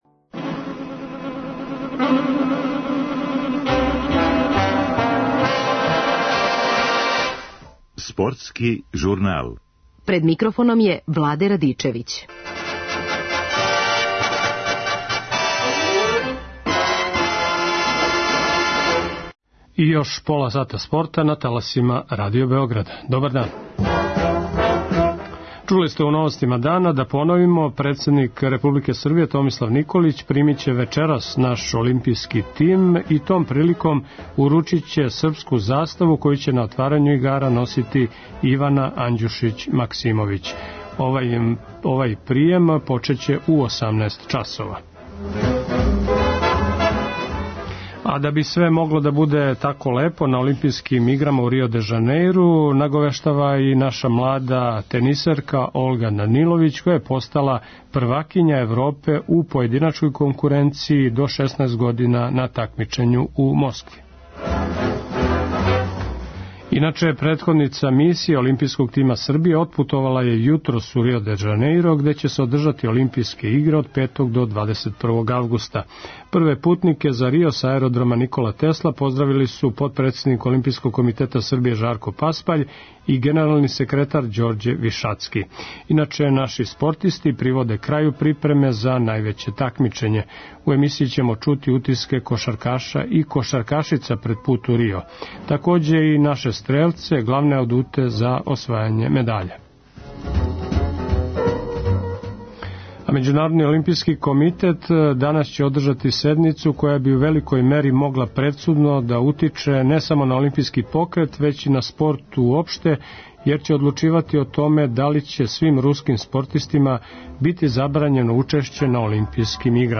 У емисији ћемо чути утиске кошаркаша и кшаркашица пред пут у Рио.